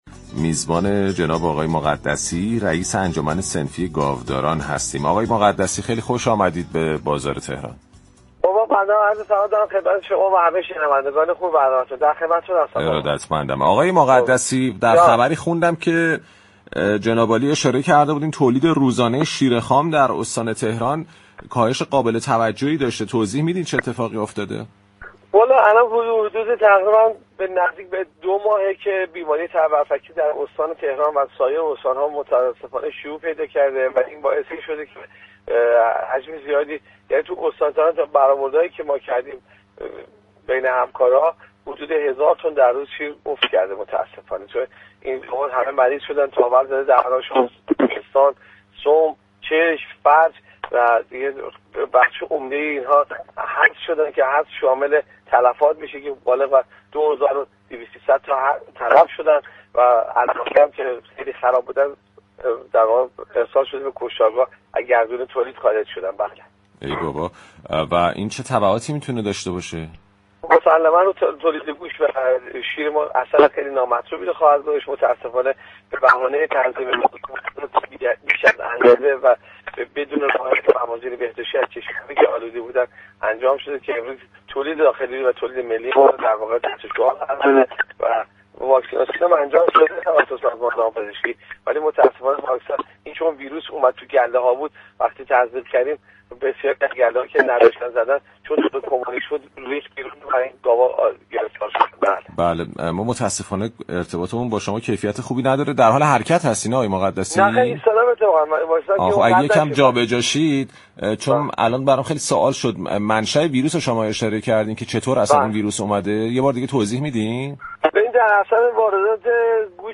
در گفت‌وگو با رادیو تهران